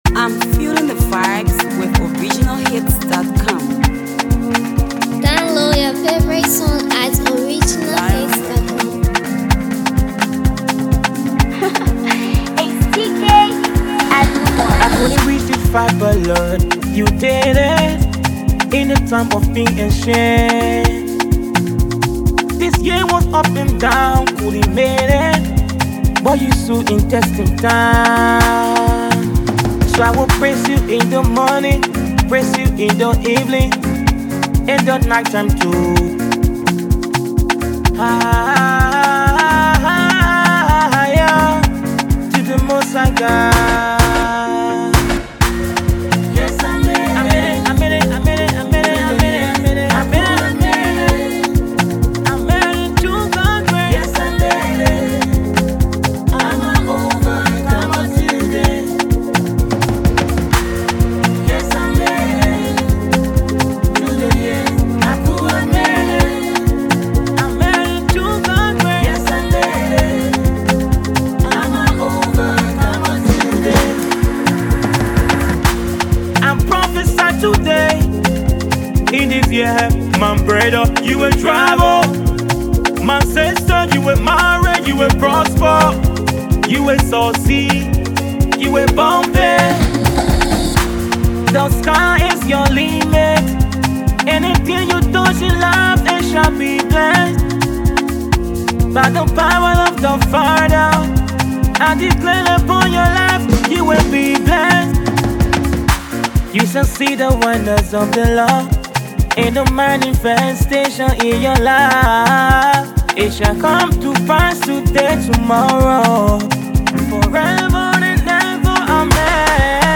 A Powerful Gospel Piece